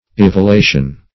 Evolation \Ev`o*la"tion\, n.